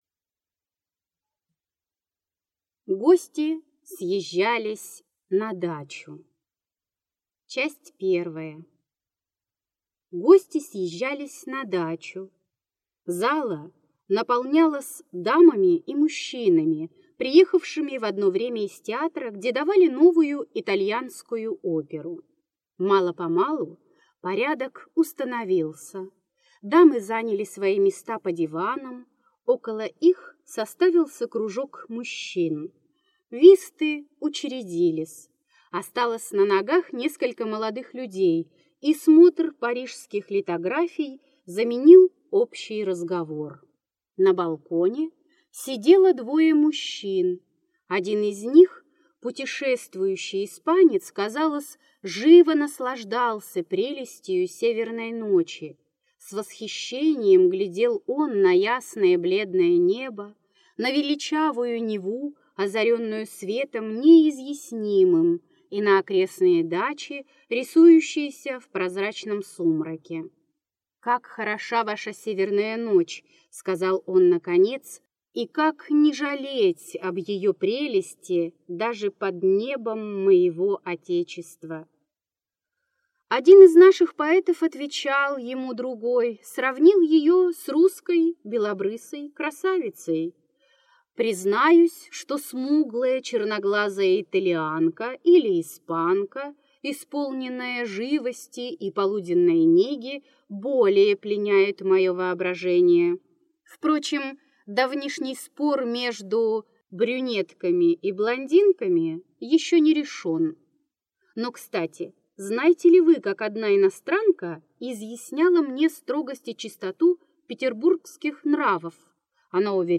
Аудиокнига Египетские ночи. Гости съезжались на дачу. История села Горюхина. Повесть из римской жизни | Библиотека аудиокниг